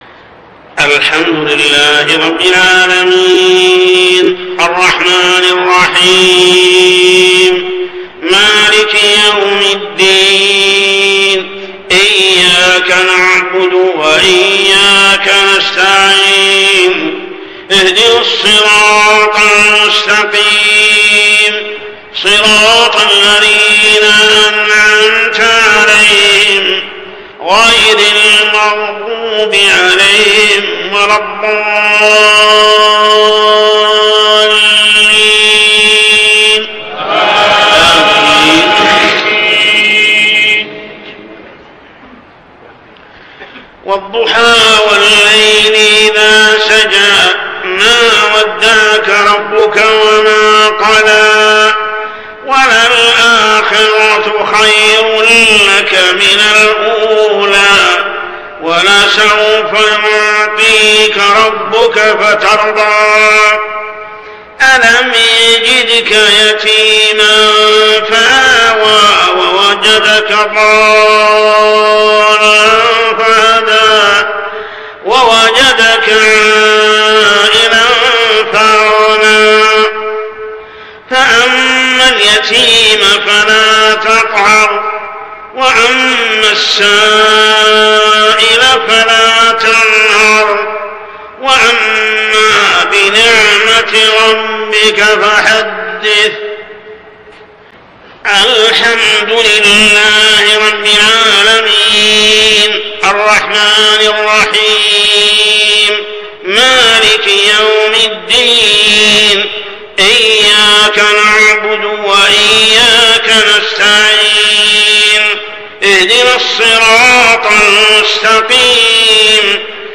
صلاة العشاء 2-9-1423هـ سورتي الضحى و الشرح كاملة | Isha prayer Surah Ad-Duha and Ash-Sharh > 1423 🕋 > الفروض - تلاوات الحرمين